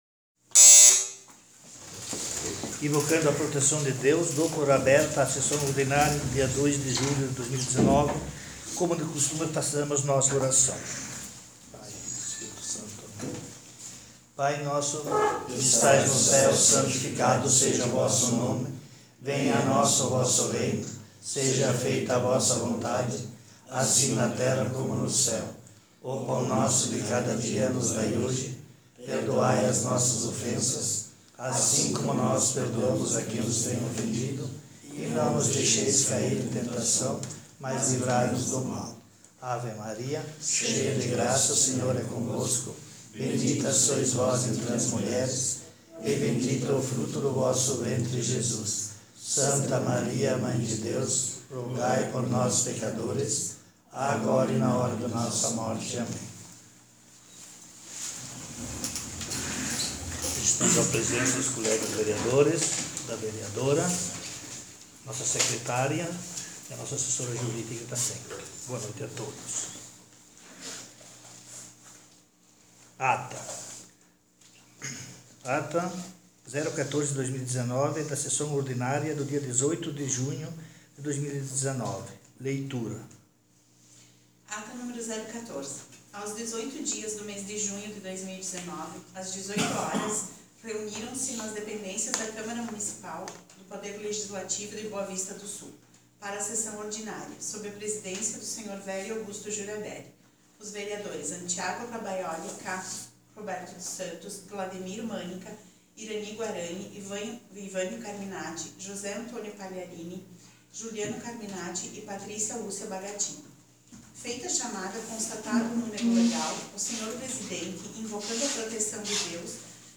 Sessão Ordinária dia 02/07/19